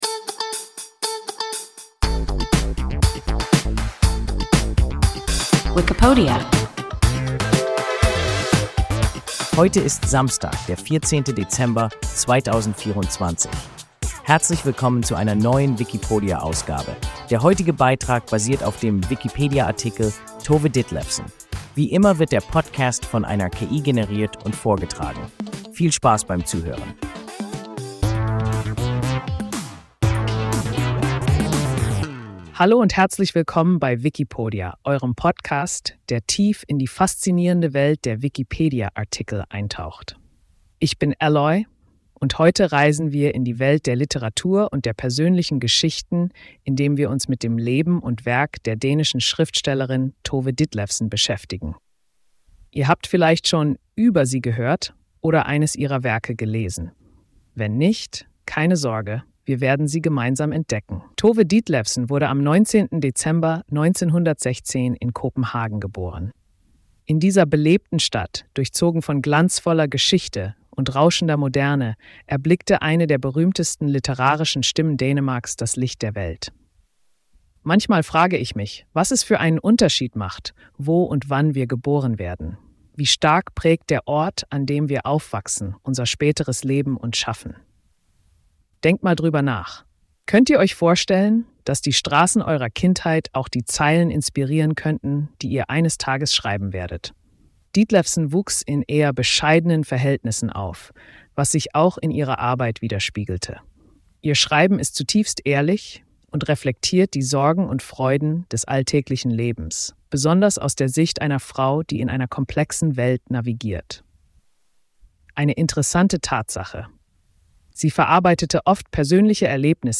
Tove Ditlevsen – WIKIPODIA – ein KI Podcast